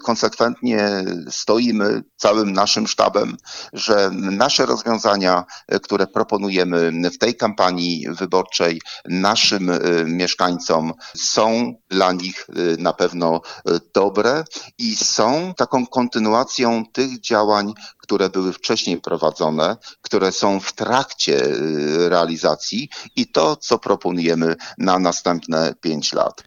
Samorządowiec mówił na naszej antenie, że każdy ma prawo kandydować i mieć swoją wizje rozwoju gminy. Zaznaczył, że mieszkańcy dokonają takiego wyboru, który będzie dla nich najlepszy.